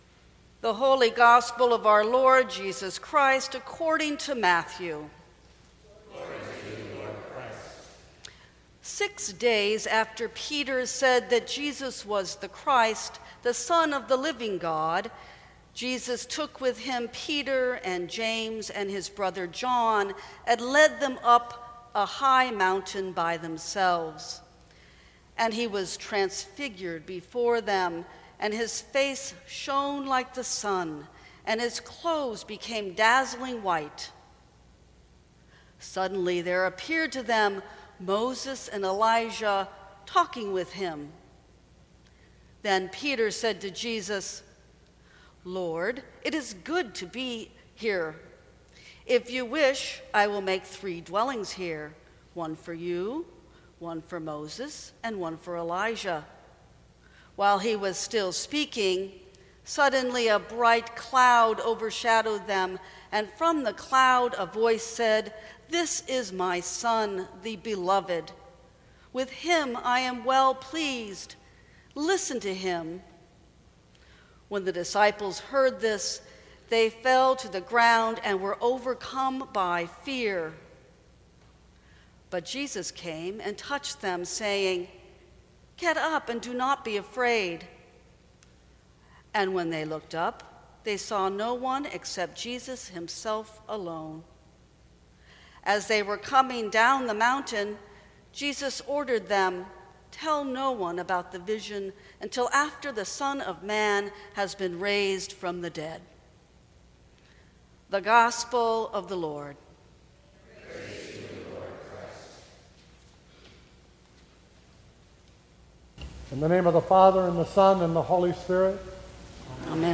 Sermons from St. Cross Episcopal Church Holy Places Feb 26 2017 | 00:14:41 1x 00:00 / 00:14:41 Subscribe Share Apple Podcasts Spotify Overcast RSS Feed Share Link Embed